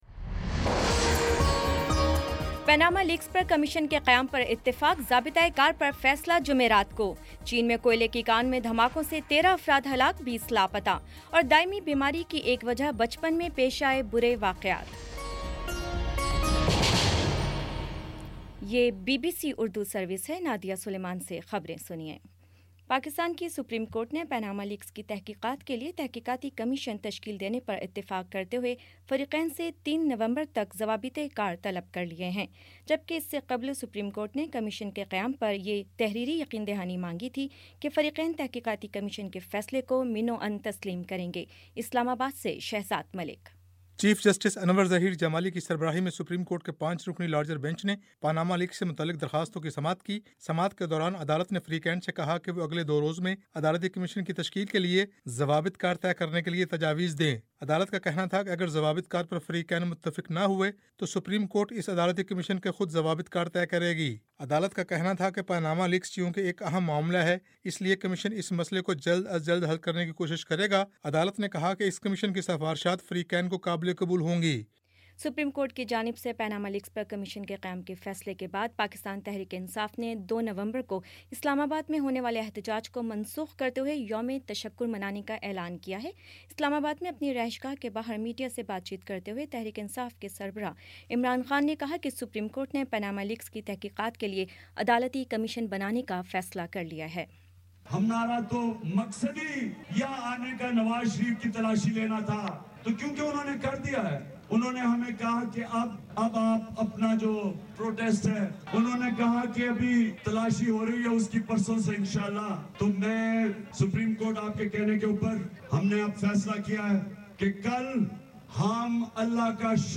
نومبر 01 : شام پانچ بجے کا نیوز بُلیٹن